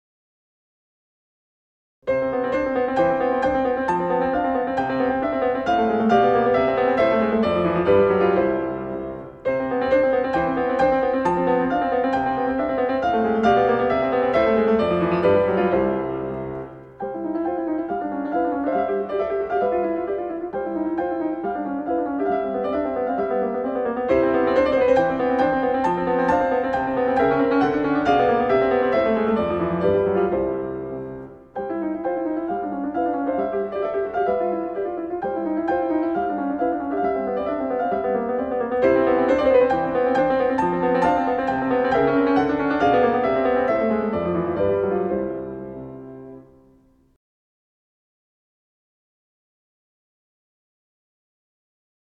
Un buen ejemplo de tema con variaciones son las variaciones para piano K. 265 de Mozart, sobre la conocidísima canción “Ah, vous dirais-je maman”.